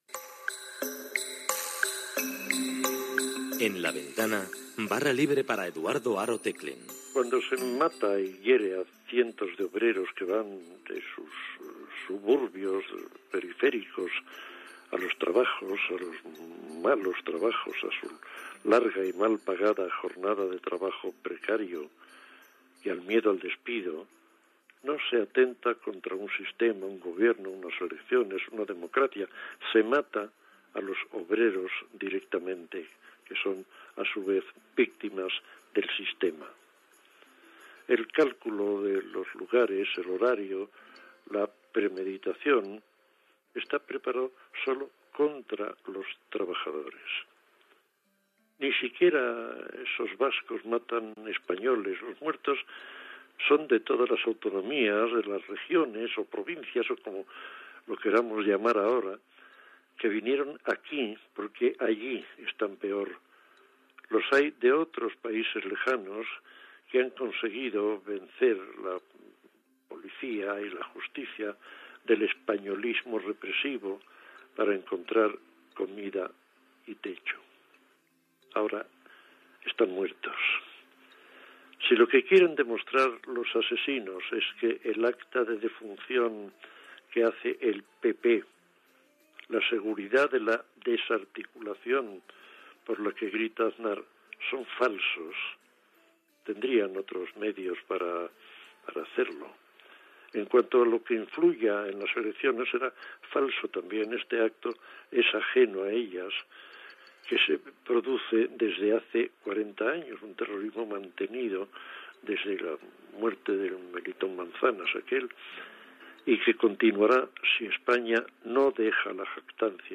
Programa especial fet des del carrer Téllez número 30.
Entreteniment